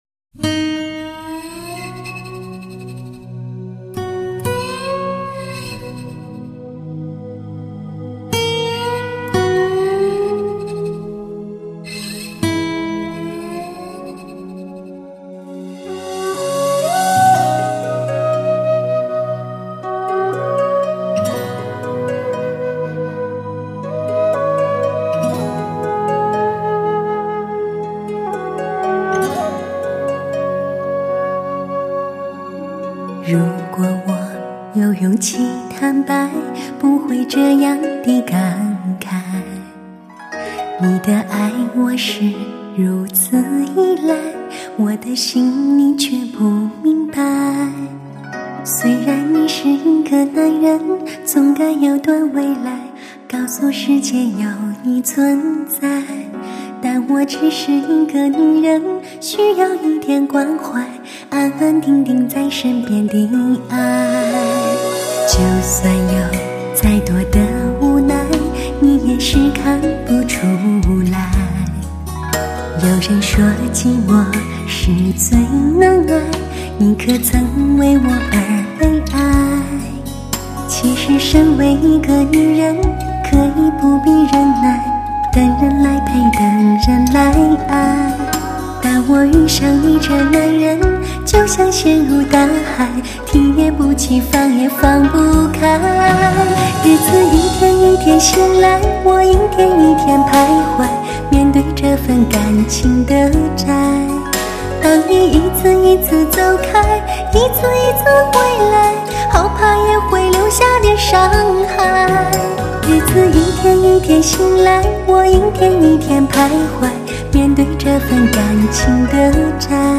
音效 超震撼 最佳现场感
再续第一张精彩纷呈的路线，令人屏息的纯净录音，